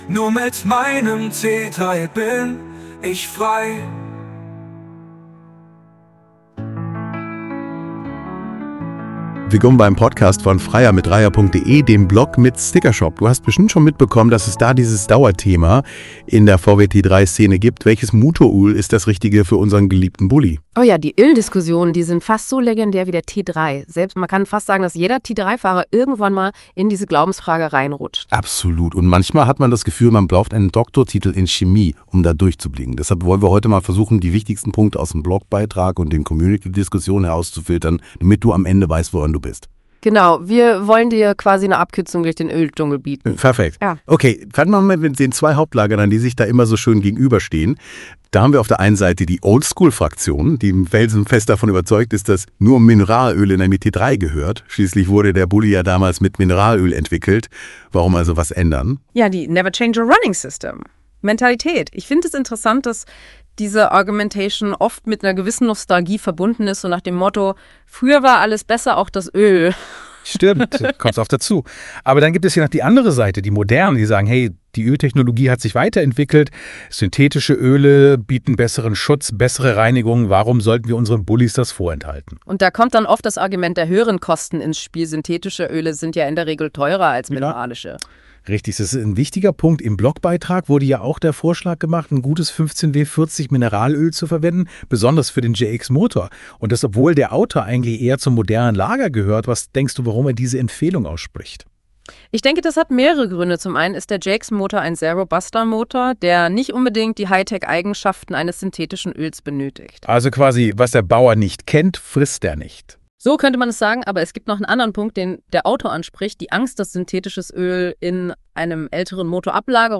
KI-Podcast: Die Öldiskussion – Alles andere als geschmeidig